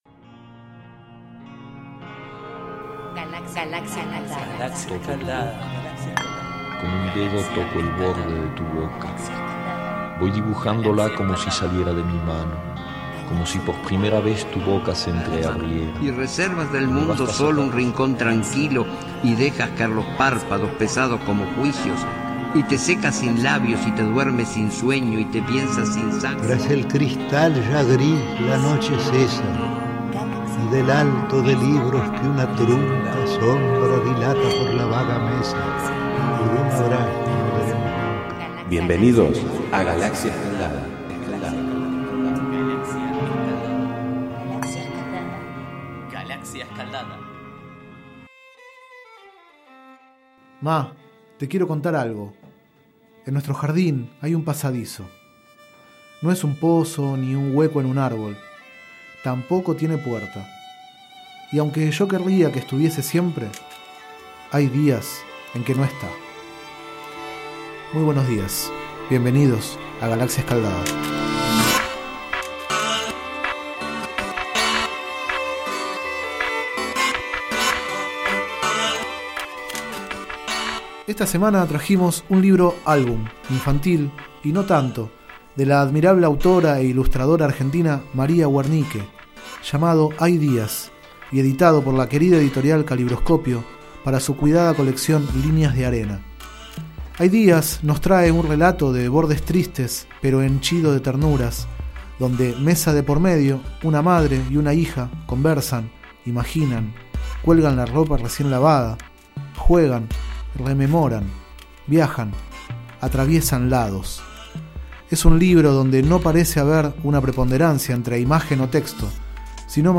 06º micro radial, realizado el 27 de abril de 2013, sobre el libro Hay días, de María Wernicke.